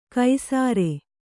♪ kai sāre